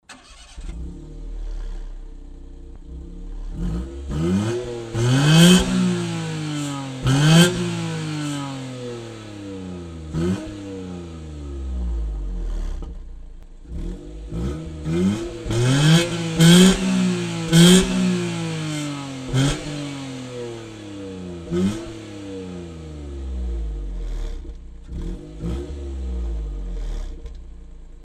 アイドリングとエンジンを回した時の音量の差を再現する為に
ダイナミックレンジを広くとっていますので
アイドリングの音が聞き取りづらいですが
エンジンが回ると音量が大きくなりますので
ではでは・・エンジン始動からの排気音をお聞き下さい。
（フジツボ レガリスKタイプ2＋HKSパワーフロー）